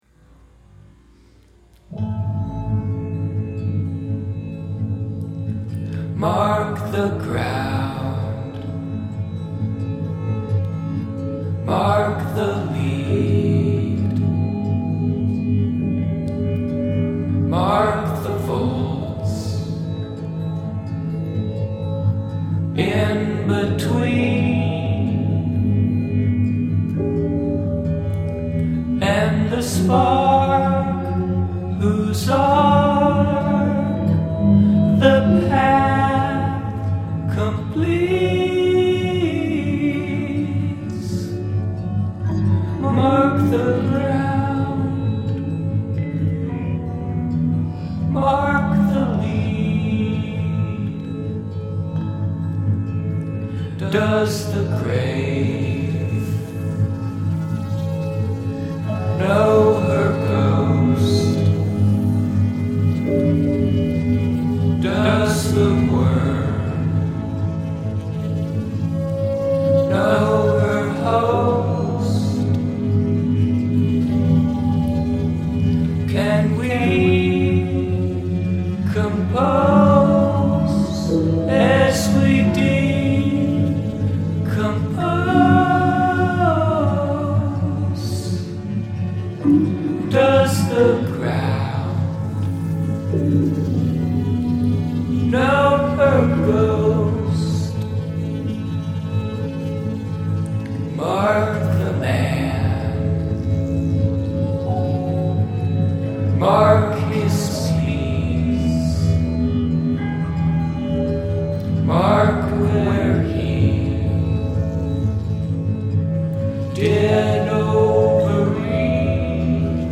verse: C, G7, C, G7, Am, G7, C, F, C, G7, C
verse, verse, verse